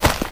Footstep3.wav